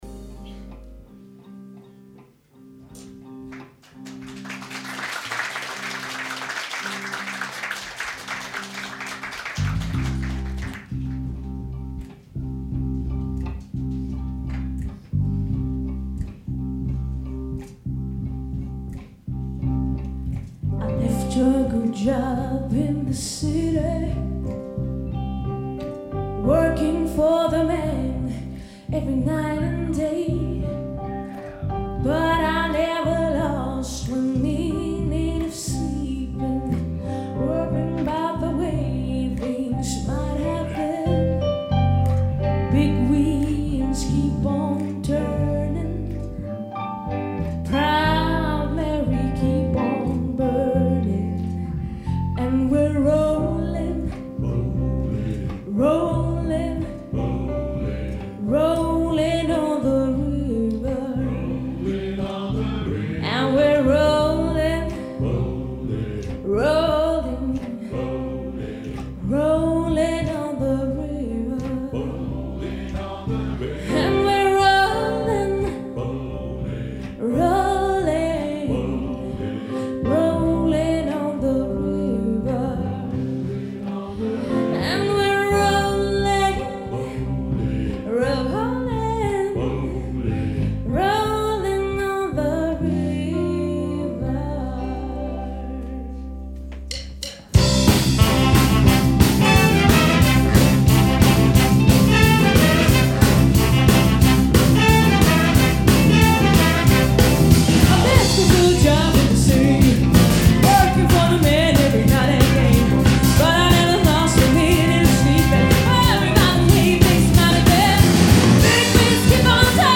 • Bigband